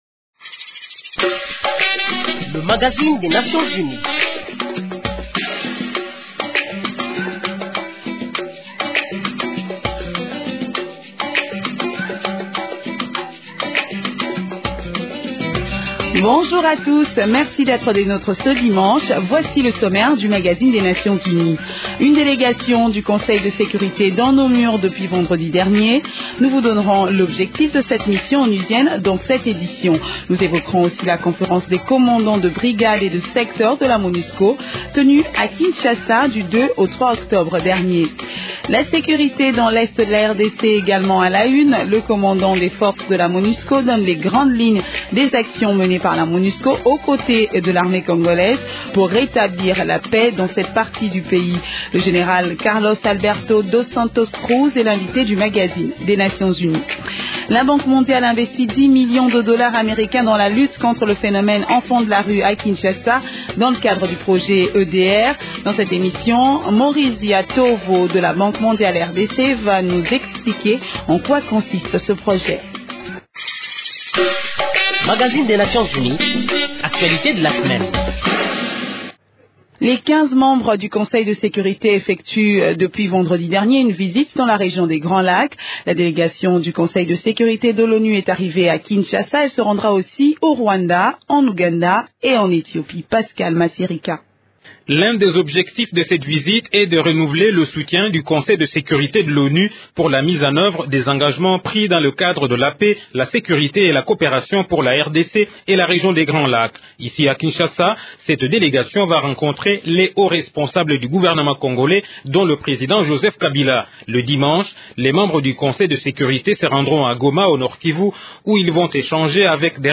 L’ambassadeur Alexis Lamek, Représentant permanent de la France auprès des Nations unies et son homologue Mohammed Loulichki du Maroc, le 4/10/2013 à Kinshasa, lors d’une interview exclusive accordée à Radio Okapi, pendant leurs visites du travail en RDC.